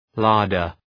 Shkrimi fonetik {‘lɑ:rdər}